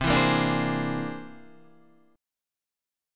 C+9 chord {8 7 8 7 9 8} chord